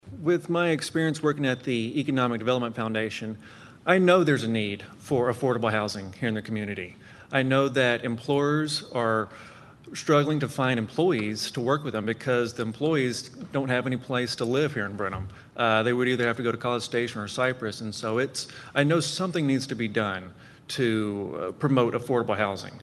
Transparency, affordable housing, and the cost of living in Brenham were just a few of the topics addressed by candidates for the Brenham City Council at Wednesday’s Candidates Forum at Brenham National Bank, hosted by KWHI.